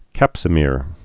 (kăpsə-mîr) or cap·so·mer (-mər)